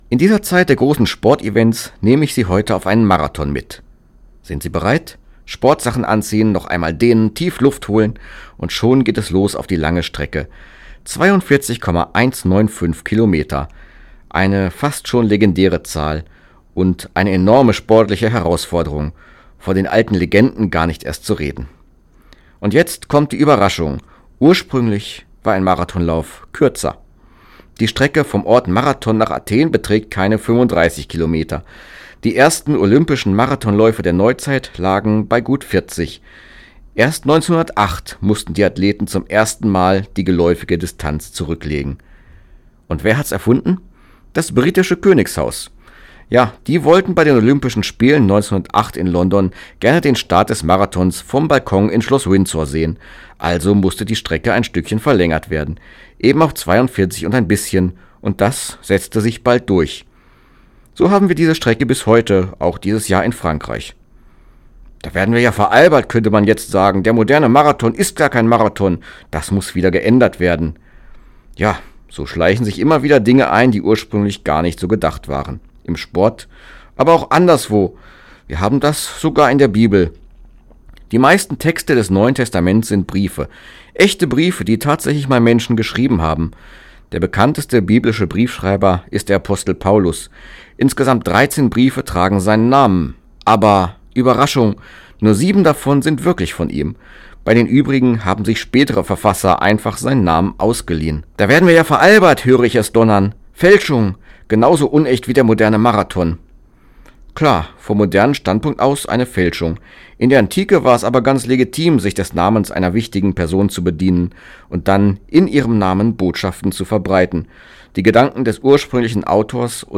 Radioandacht vom 10. Juli